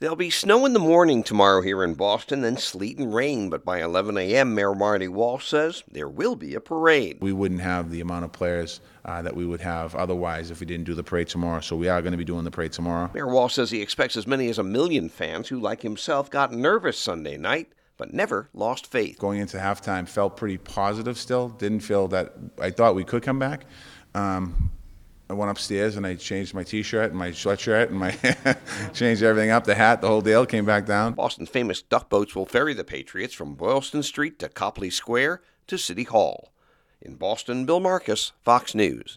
MAYOR WALSH TELLING REPORTERS SAYING THAT THE PATS HAVE – QUOTE – MADE BOSTON AND NEW ENGLAND PROUD.